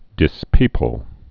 (dĭs-pēpəl)